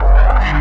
Bass 1 Shots (110).wav